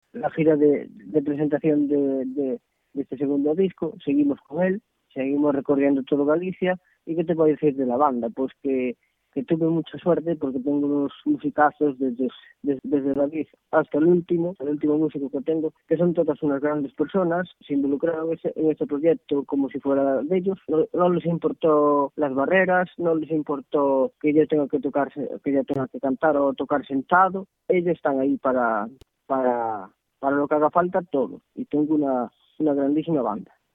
Luchando, luchando... aquí estoy ahora formato MP3 audio(0,18 MB), comenta risueño, al otro lado del hilo telefónico, dedicándonos un alto en el camino de su gira para compartir con nosotros emociones, sentimientos a flor de piel y el deseo, que atisba cada vez más cercano, de alcanzar la gloria del artista con mayúsculas.